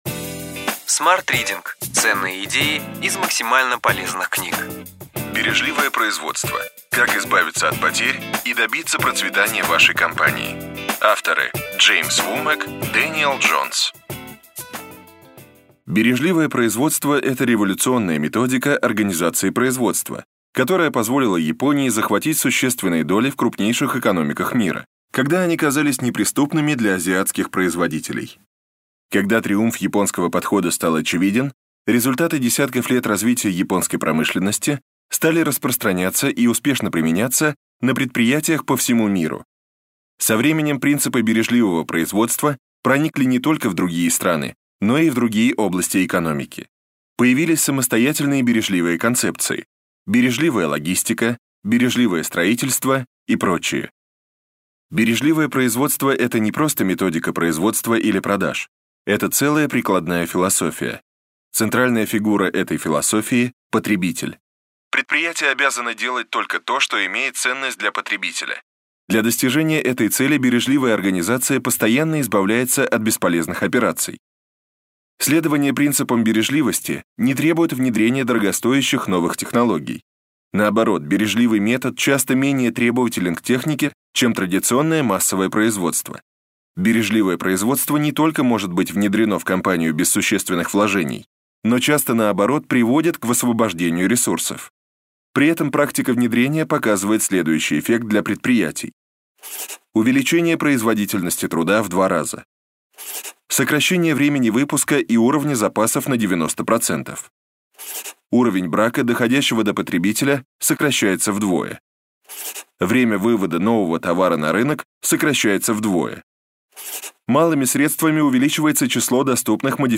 Аудиокнига Ключевые идеи книги: Бережливое производство. Как избавиться от потерь и добиться процветания вашей компании.